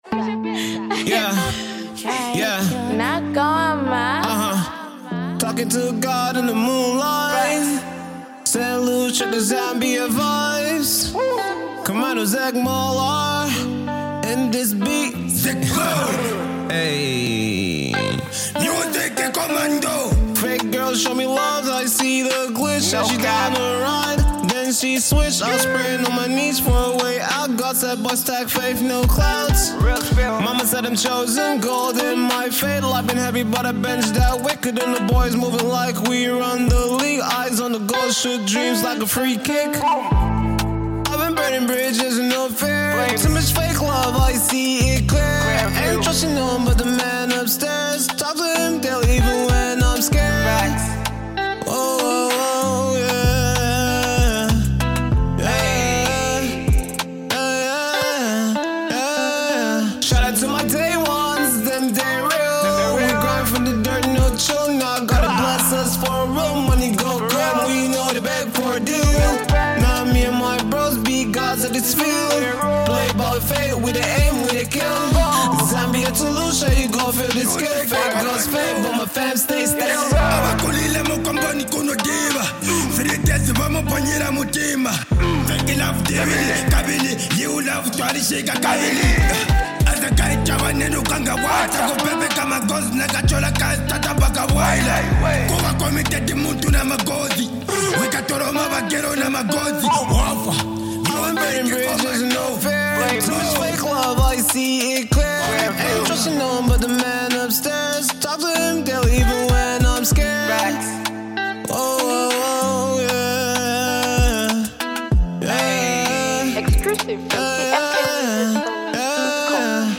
inspiring and uplifting song